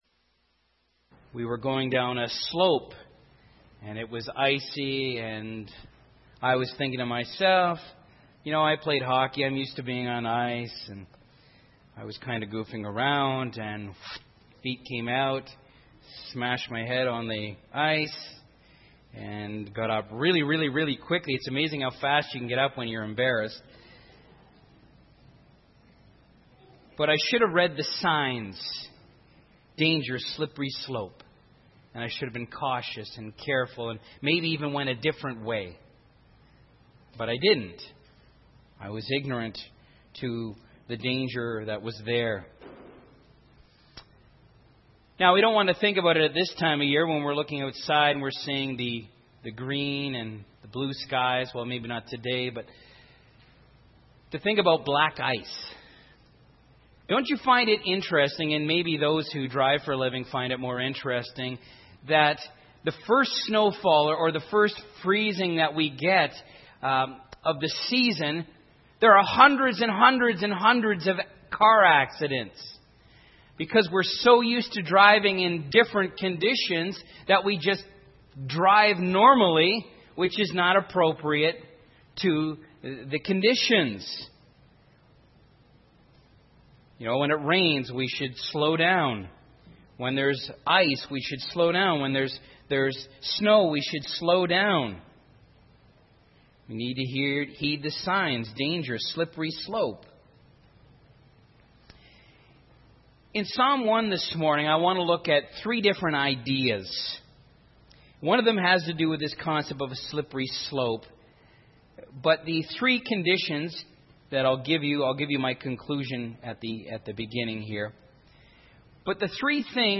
Groping In The Dark Sermons